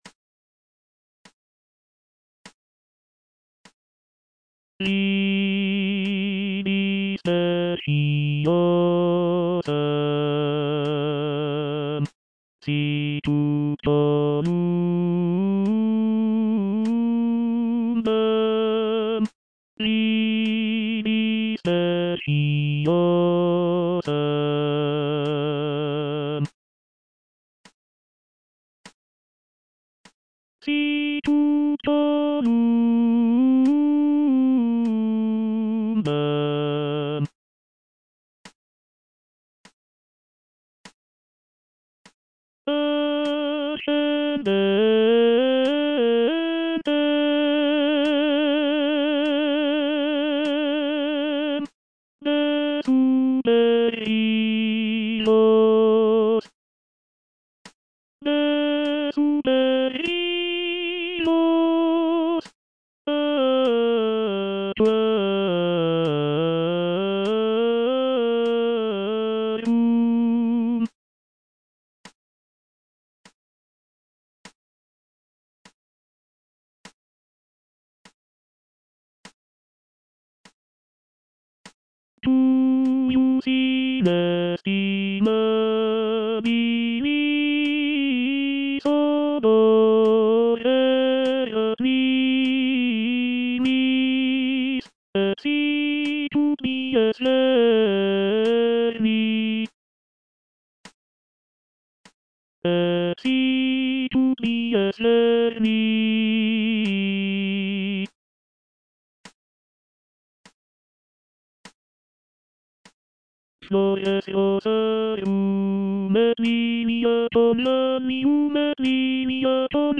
"Vidi speciosam" is a choral motet composed by Raffaella Aleotti, an Italian nun and composer from the late Renaissance period. The piece is written for four voices and is known for its beautiful and expressive melodies.